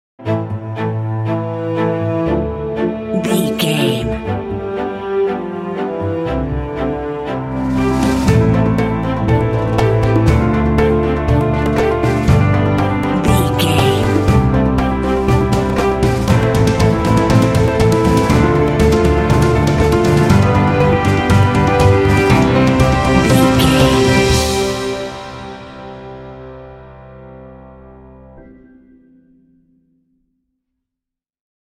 Epic / Action
Fast paced
Ionian/Major
Fast
powerful
dreamy
drums
percussion
cinematic
film score